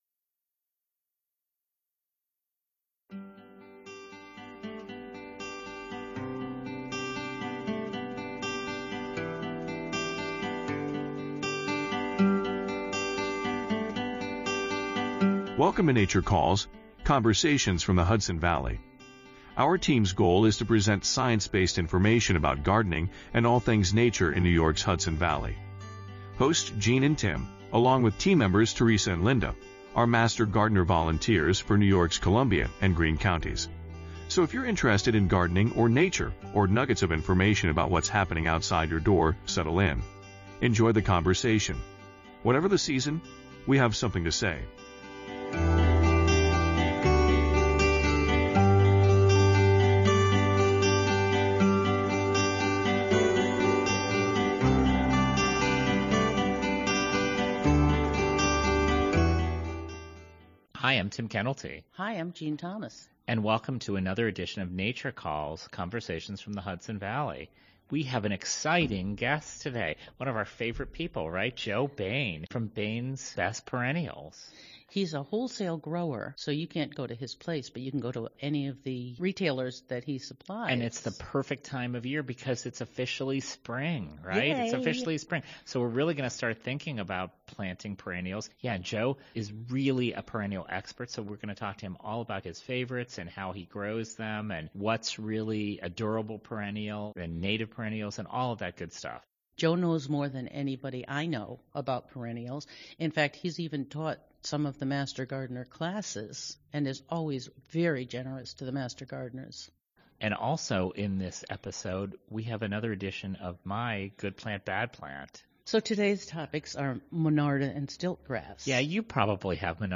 Nature Calls, Conversations from the Hudson Valley